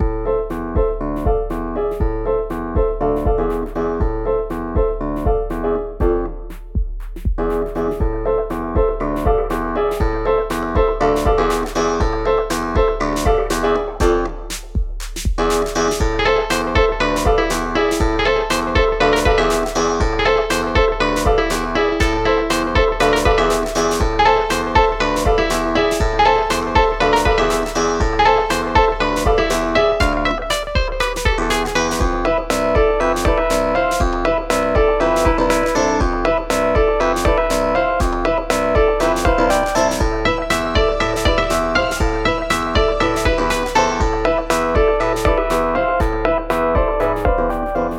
【イメージ】エレクトロ・スウィング など